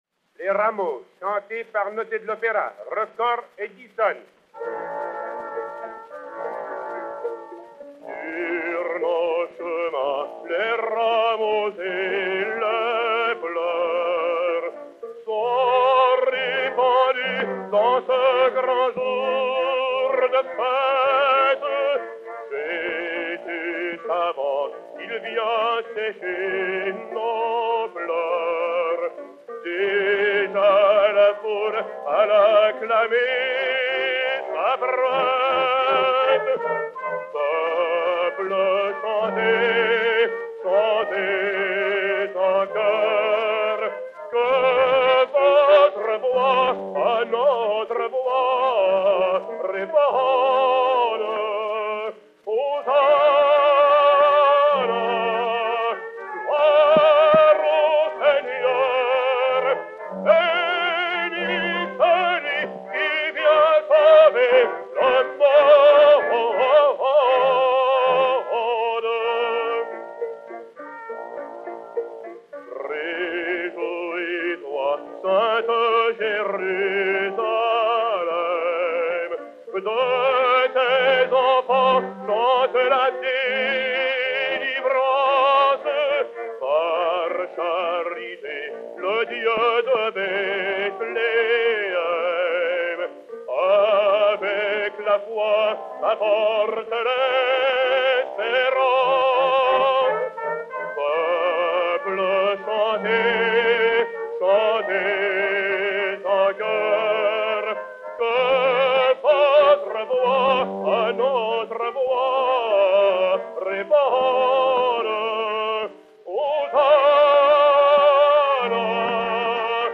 Hymne, poésie de Jules BERTRAND, musique de Jean-Baptiste FAURE (1864).
Jean Noté et Orchestre
Cylindre Edison 17377, enr. en 1907